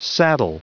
Prononciation du mot saddle en anglais (fichier audio)
Prononciation du mot : saddle